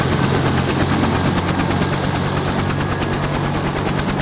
propellersstart.ogg